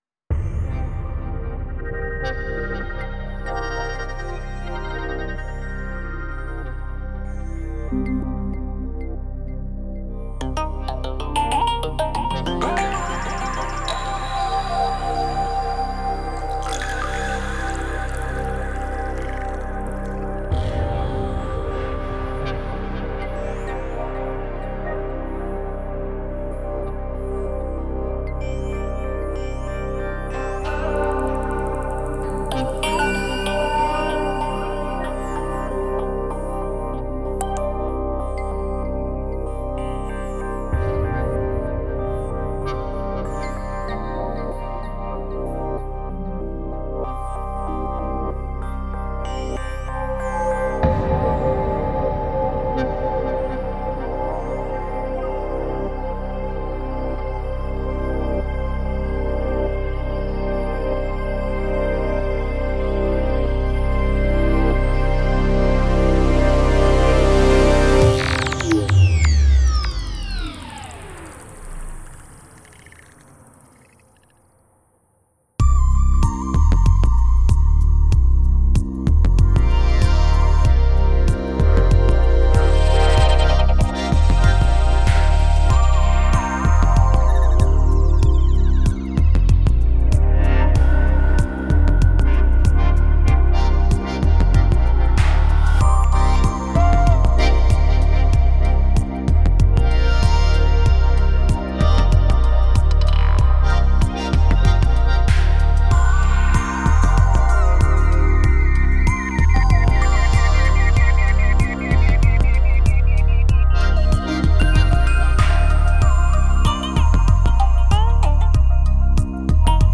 Весёлая (хаус
Хочу сказать, что потрясен работой - все звучит прозрачно, интересно и не шаблонно!